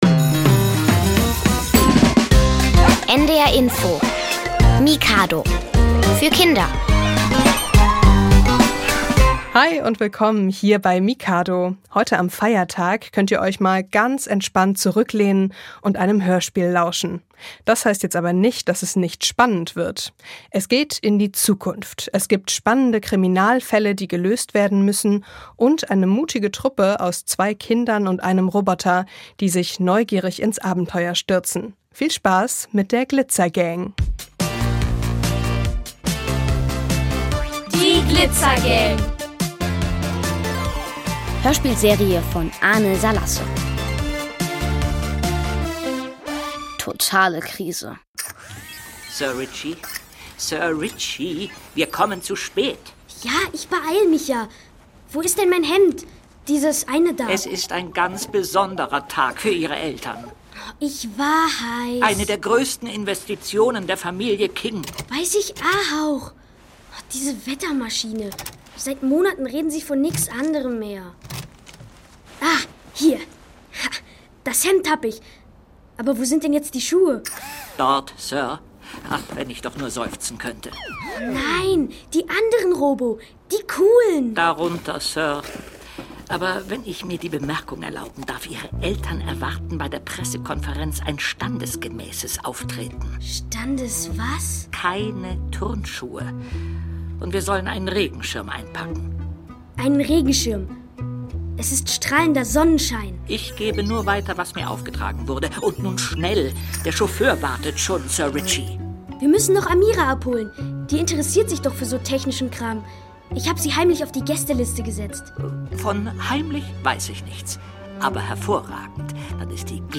Kinderhörspiel: Glitzer-Gang (Folge 5 + 6) ~ Hörspiele, Geschichten und Märchen für Kinder | Mikado Podcast